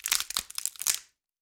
Bone_Break_21.wav